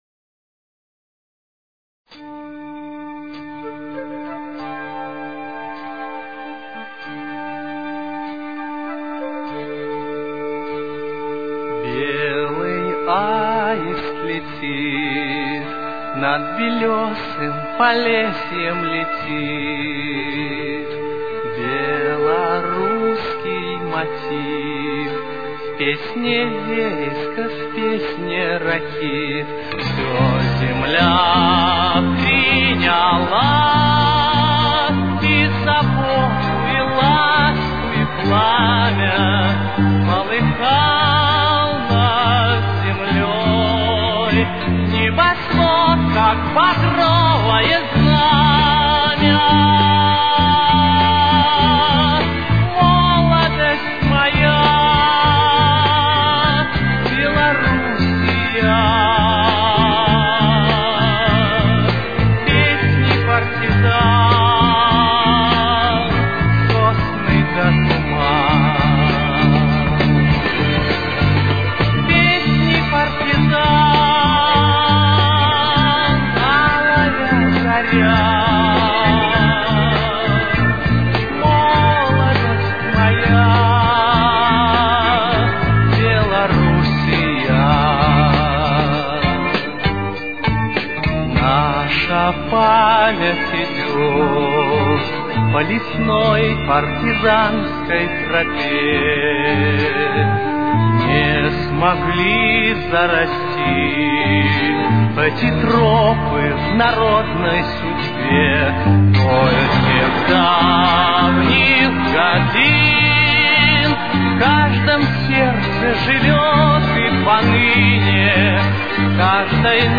Тональность: Ре минор. Темп: 103.